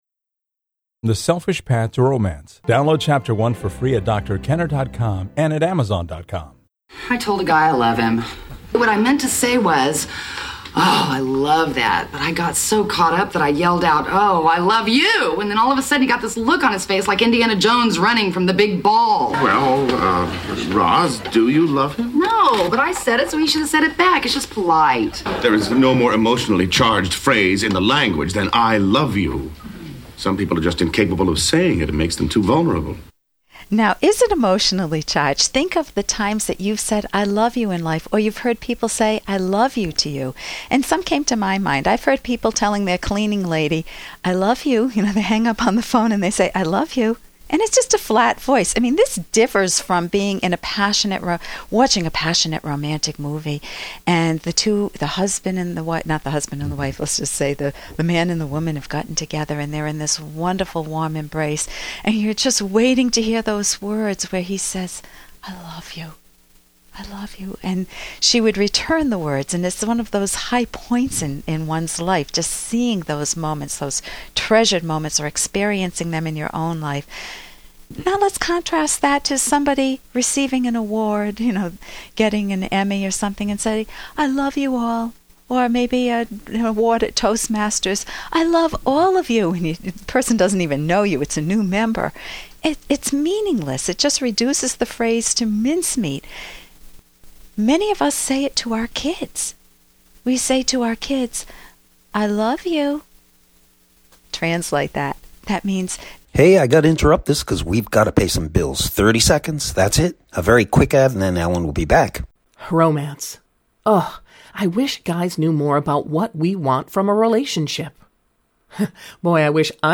Episode from The Rational Basis of Happiness® radio show